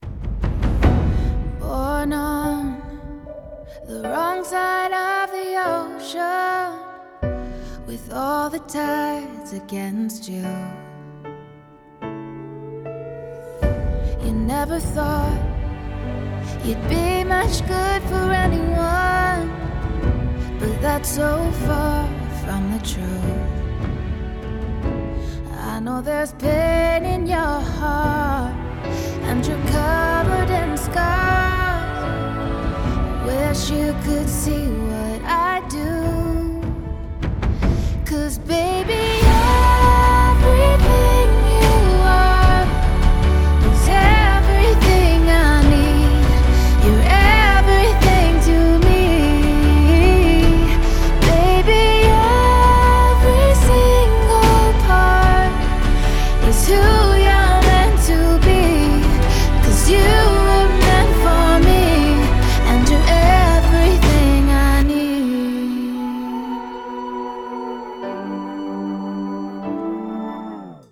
• Качество: 320, Stereo
мелодичные
саундтреки
спокойные
красивая мелодия
красивый женский голос